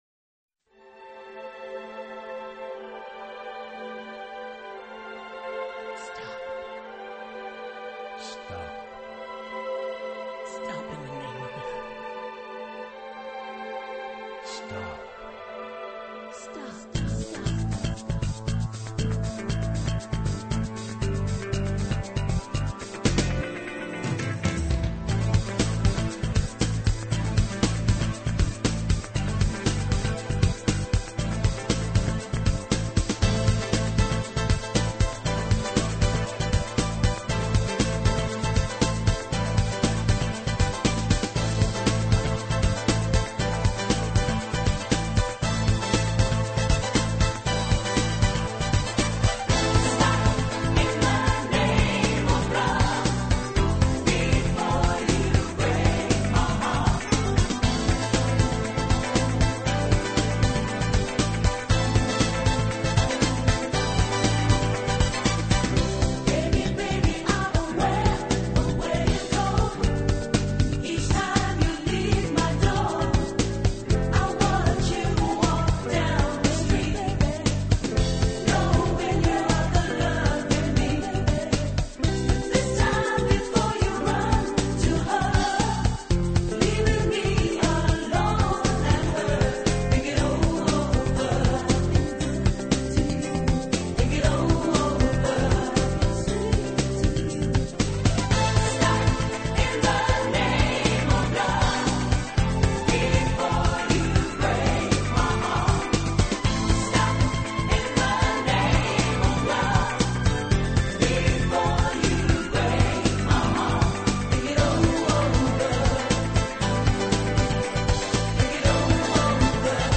【顶级轻音乐】
以热烈的旋律，独特的和声赢得千百万听众